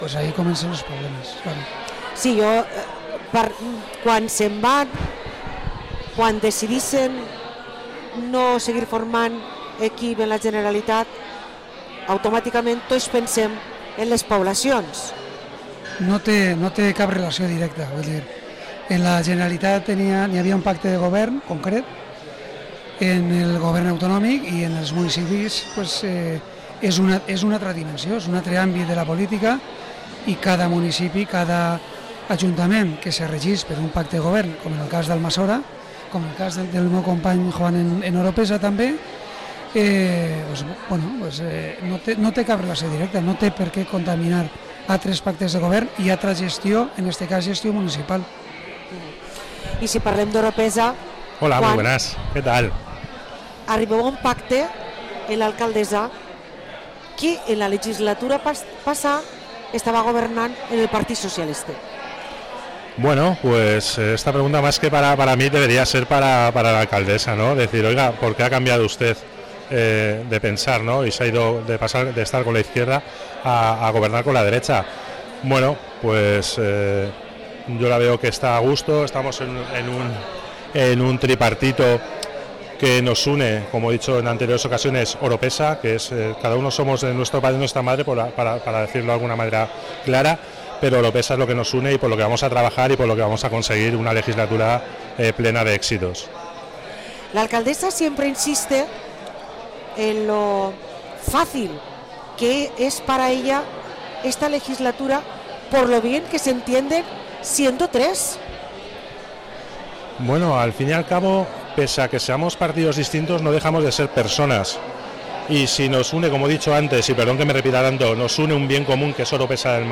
Parlem amb Juan García i Vicente Martínez, regidors de VOX en Almassora i Orpesa